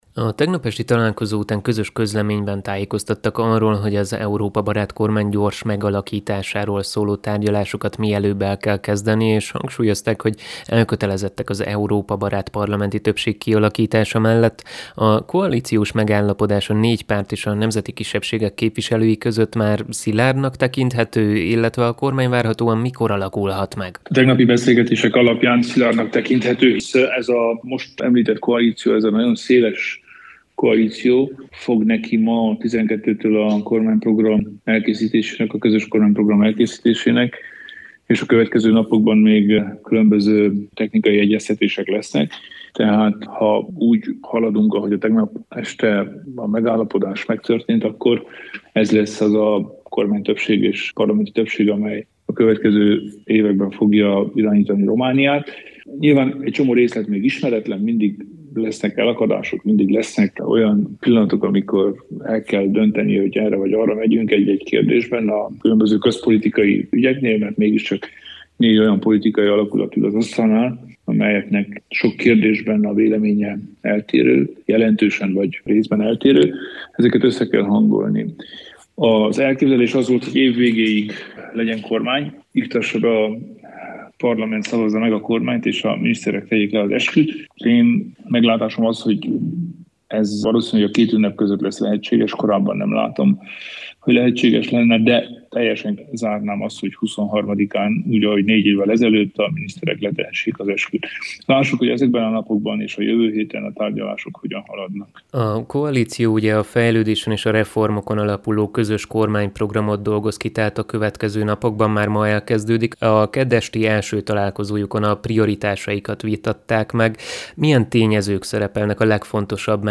Az RMDSZ elnöke, Kelemen Hunor a Kolozsvári Rádiónak adott interjújában arról beszélt, hogy az új kormány várhatóan a két ünnep között alakulhat meg.